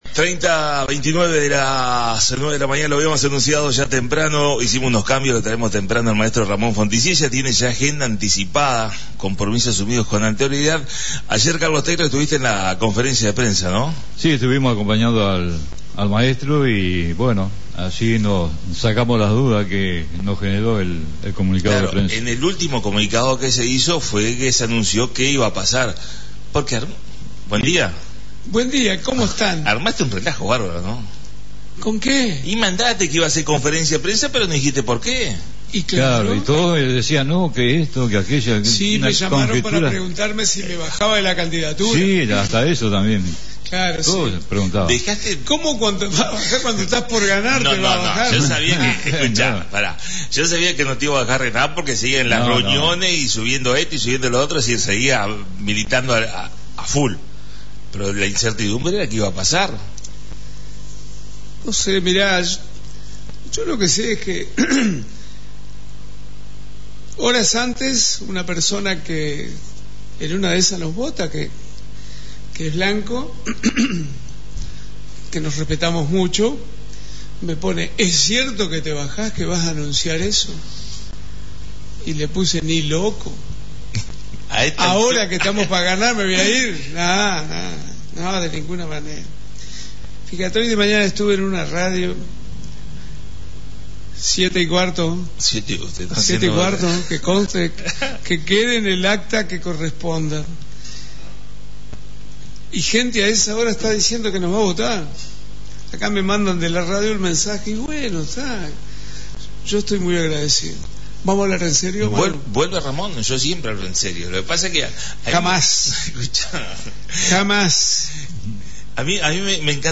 La visita a la Radio del Maestro Ramón Fonticiella Candidato a la Intendencia Salto, entrevista y mensaje final rumbo al 11 de mayo